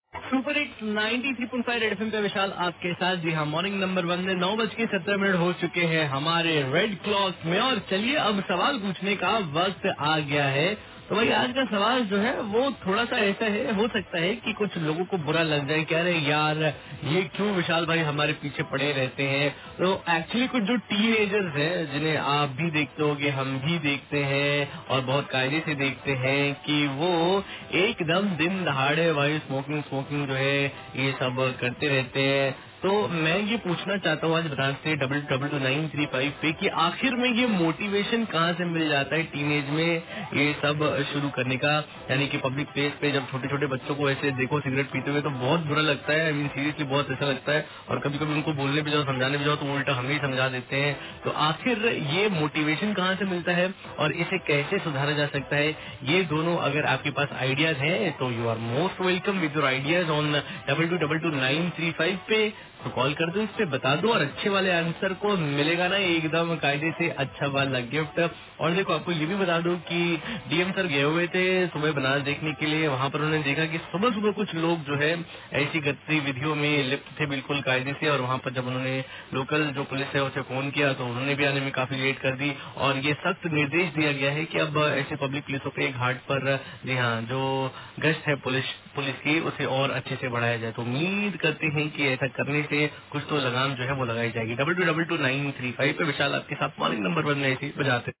WITH CALLER